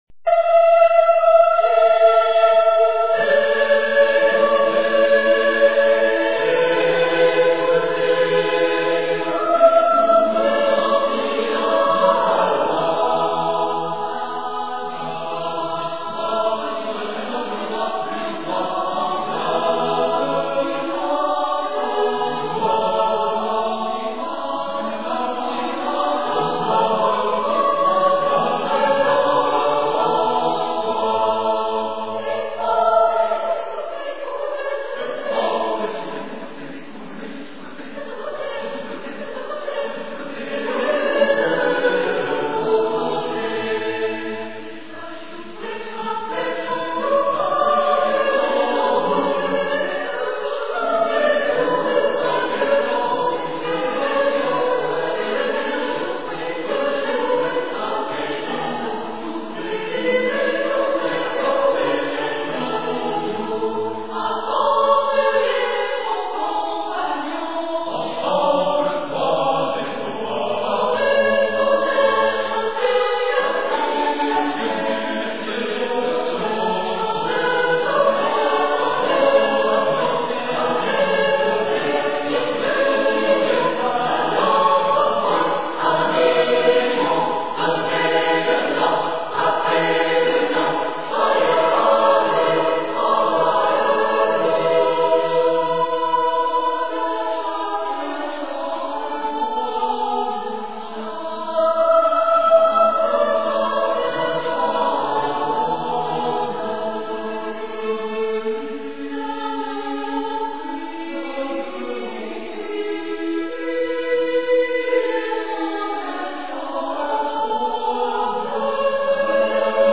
L'utilisation d'onomatopées permet cette retranscription sonore et atteint son paroxysme avec "La guerre" où une partie entière de son chant n'est que bruit d'utilisation d'arme, de cris de belligérants, d'instruments de musique présents sur le champs de bataille et de cavalcade de chevaux.
interprétée par le Chœur Universitaire de Caen Basse-Normandie
J'ai l'insigne honneur de chanter au sein de cette chorale et d'être parmi les voix de basse de ce morceau. Notre chœur est constitué d'une quarante-cinquantaine de choriste mais malheureusement "La guerre" rend toute sa saveur pour un petit chœur. Pour des raisons de copyright, la qualité sonore a volontairement été dégradée.
La première est l'appel aux armes et les préparatifs, la seconde est la bataille (avec les onomatopées simulant les sons de la bataille) ainsi que la victoire.